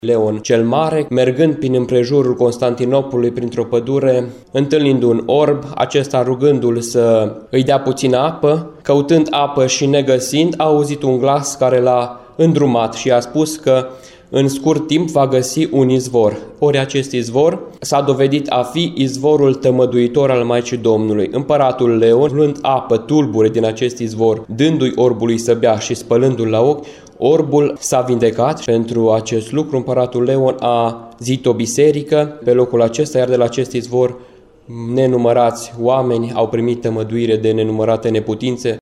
La Sfânta Liturghie a praznicului Maicii Domnului, oficiată vineri la Catedrala Mitropolitană din Timişoara au participat peste o mie de credincioşi.